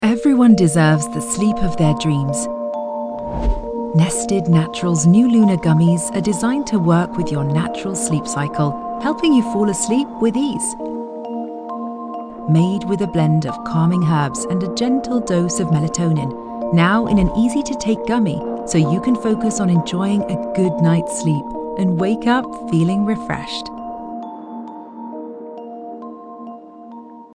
Profonde, Naturelle, Distinctive, Chaude, Douce
Commercial